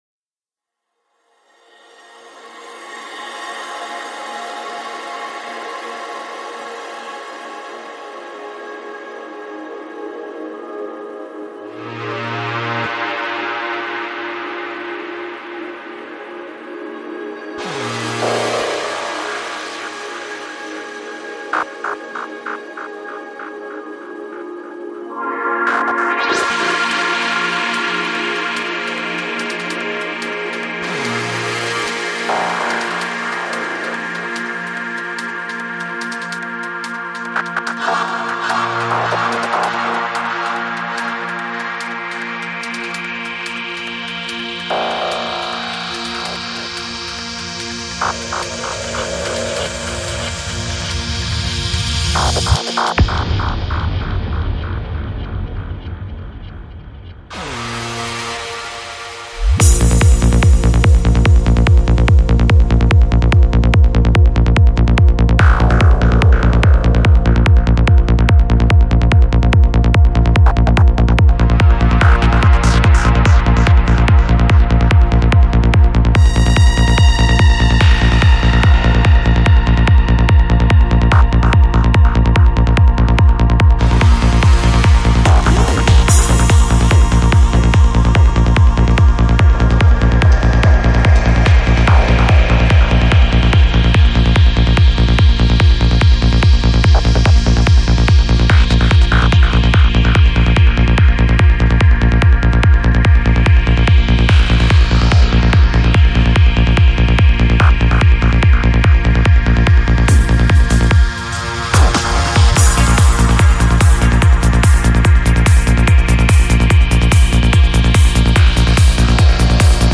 Жанр: Trance
20:06 Альбом: Psy-Trance Скачать 7.46 Мб 0 0 0